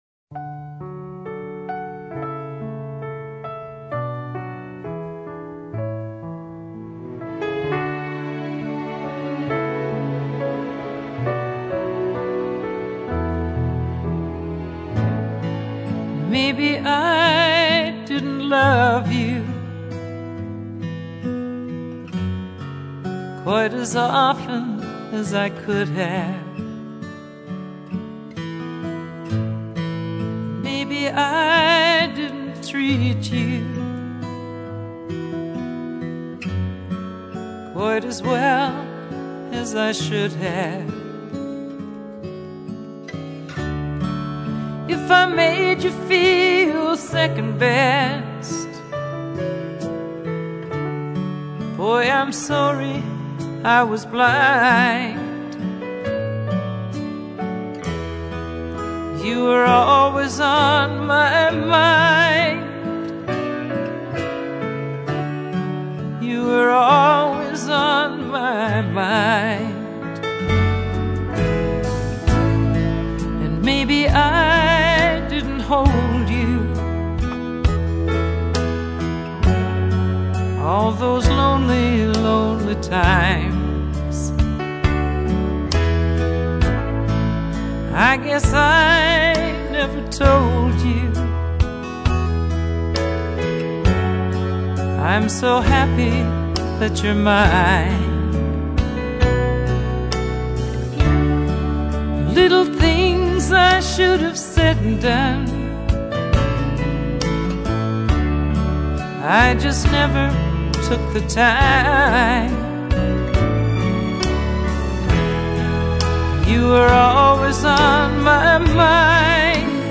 乡村老式情歌
本专辑中，大部分歌曲源于40至60年代的经典佳作，以乡村曲风为主。